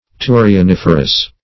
Search Result for " turioniferous" : The Collaborative International Dictionary of English v.0.48: Turioniferous \Tu`ri*o*nif"er*ous\, a. [L. turio a sprout + -ferous.] Producing shoots, as asparagus.
turioniferous.mp3